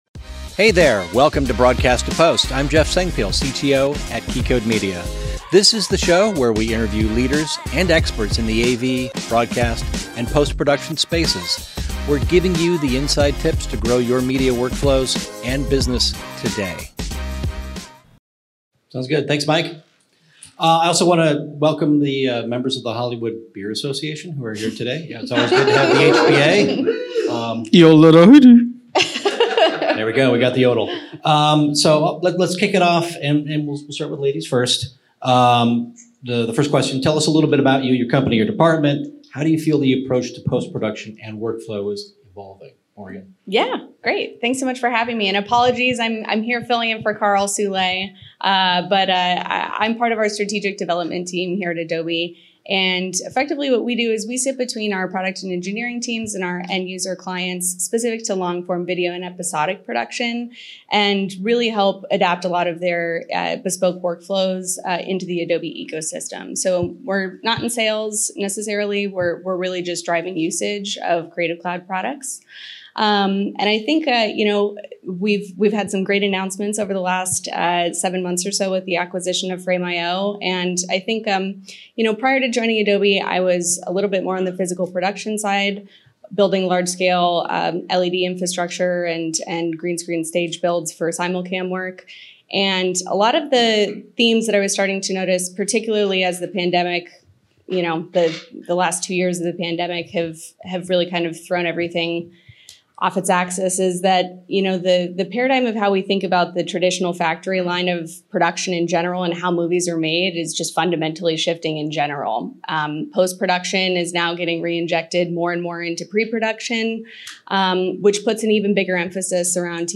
This is a live recording from our PostNAB LA event.